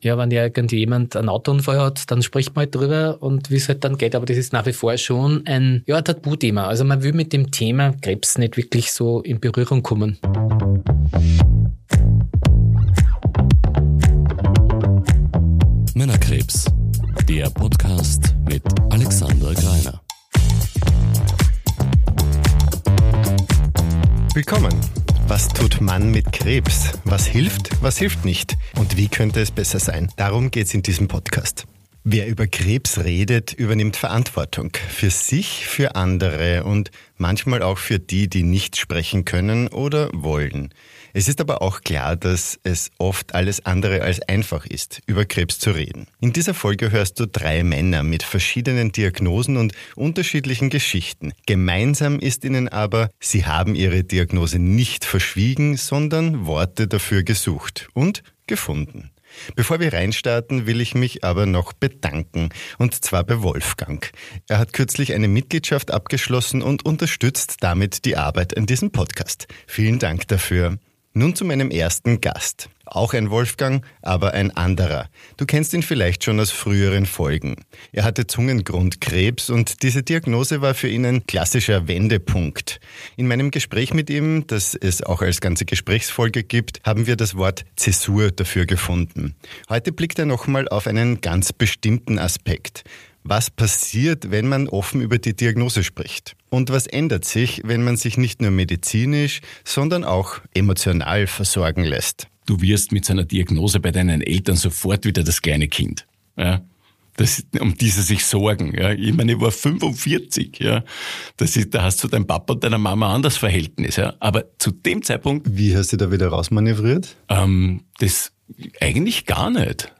In dieser Folge hörst du die Erfahrungen von drei Männern, wieder zurück in den Alltag zu finden.